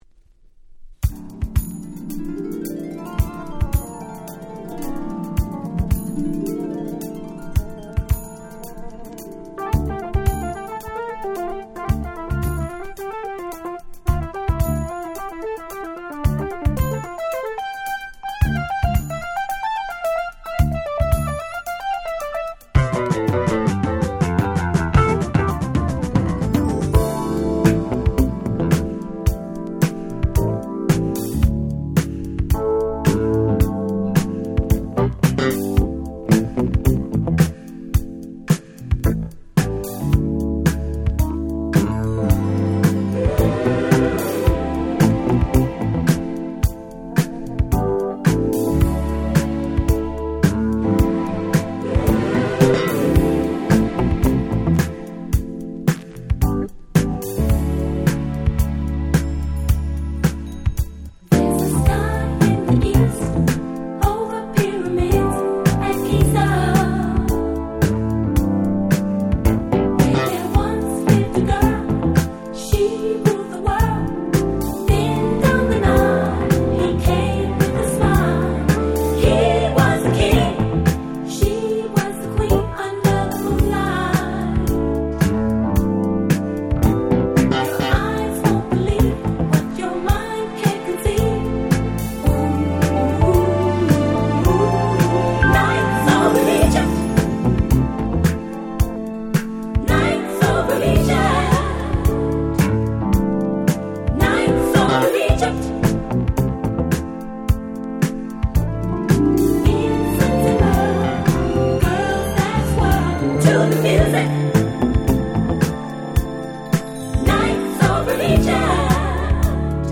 81' Super Soul Classics !!
Disco ディスコ ソウル ダンクラ ダンスクラシックス Dance Classics